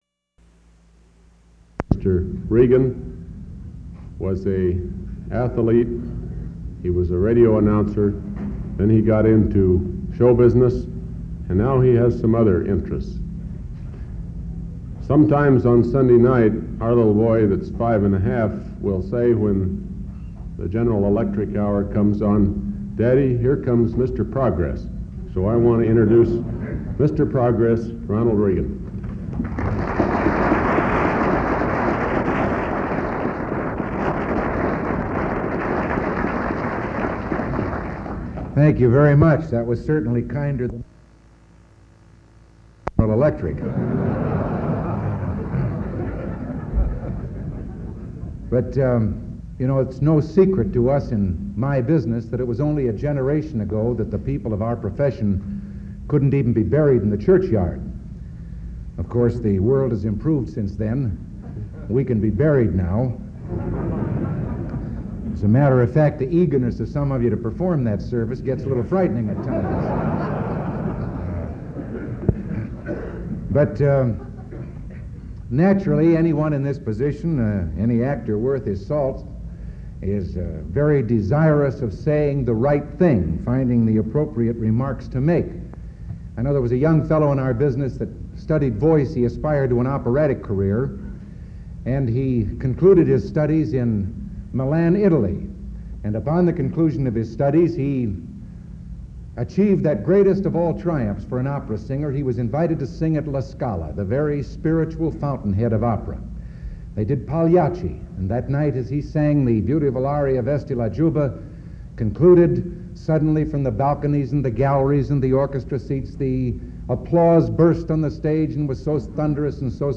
Ronald Reagan speech to California Fertilizer Association
Audio Cassette Format (WAV and CD copy) MP3 Audio file